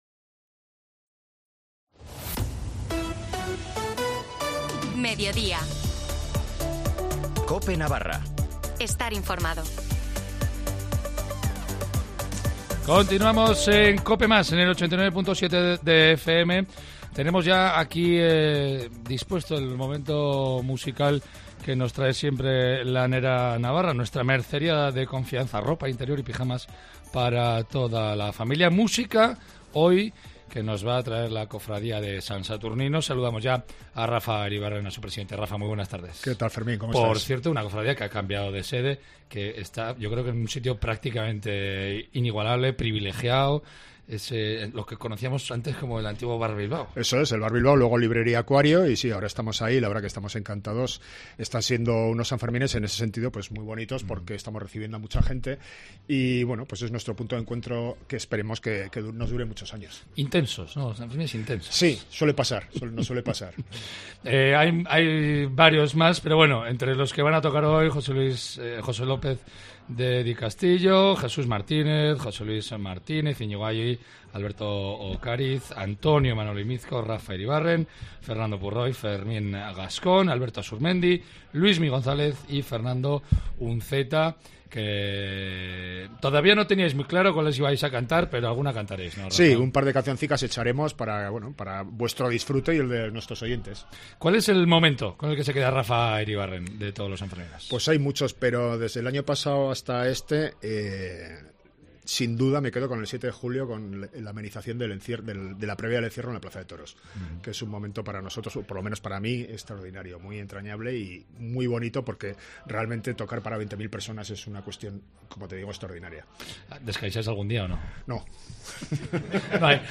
La Cofradía de San Saturnino ha cantado tres canciones en directo.